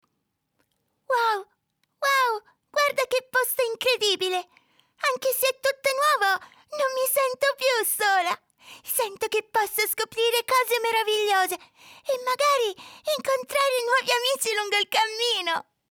Young and lively voice, that can go from a soft sound, to a super enthusiastic tone.
Sprechprobe: Sonstiges (Muttersprache):
Neumann TLM 103 Focusrite Scarlett 2i2 4th gen ProTools
Charcters - Female Kid.mp3